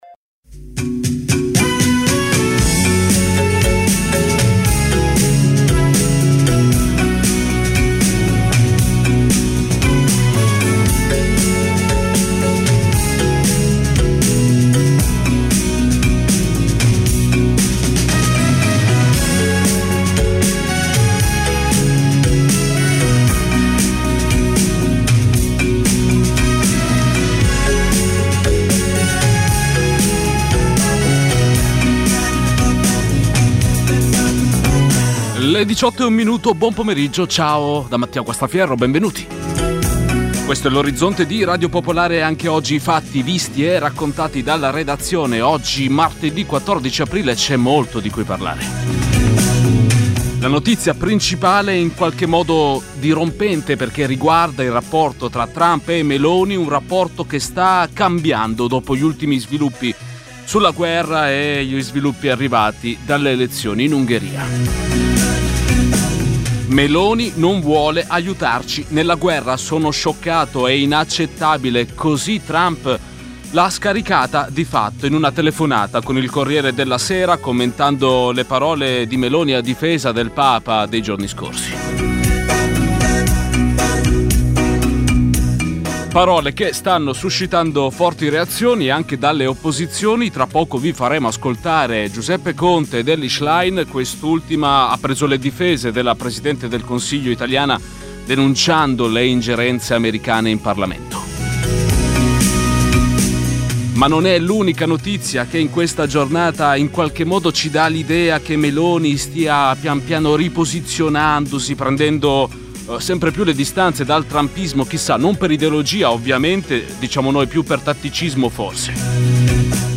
Dalle 18 alle 19 i fatti dall’Italia e dal mondo, mentre accadono. Una cronaca in movimento, tra studio, corrispondenze e territorio. Senza copioni e in presa diretta.